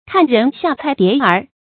看人下菜碟儿 kàn rén xià cài dié ér
看人下菜碟儿发音